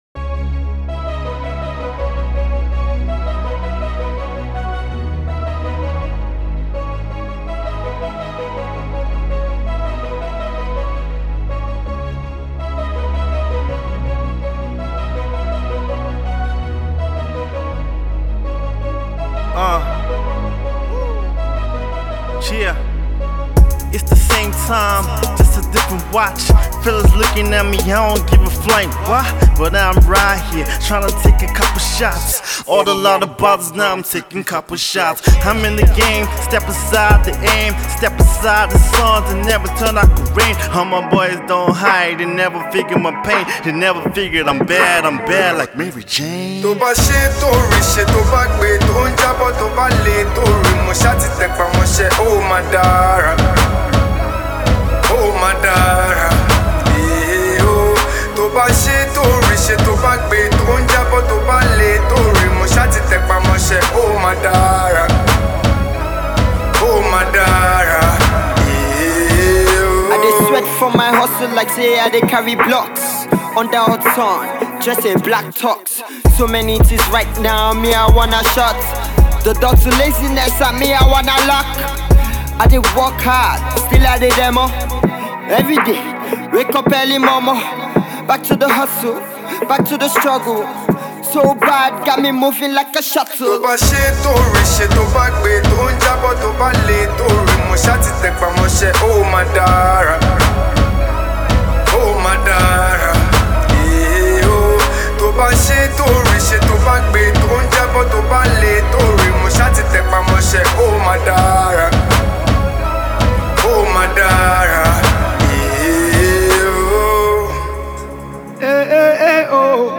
delivers a catchy hook on the hip-hop themed instrumental